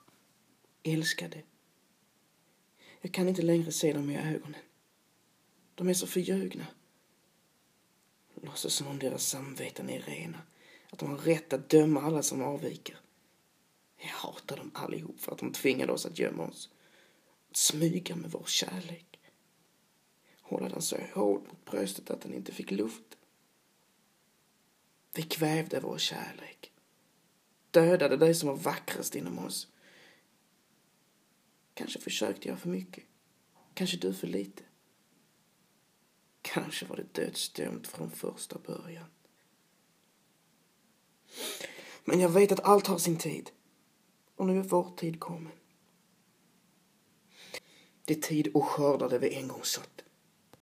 RÖST
Lätt skånska
Älskade-skånska-1.m4a